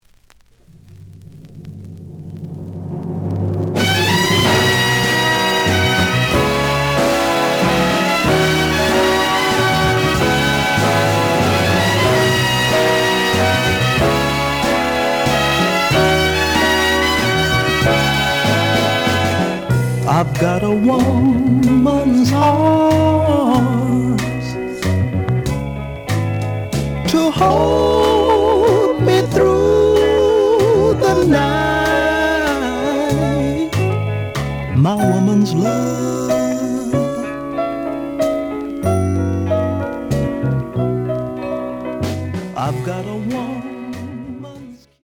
The audio sample is recorded from the actual item.
●Format: 7 inch
●Genre: Soul, 60's Soul
Looks good, but slight noise on both sides.)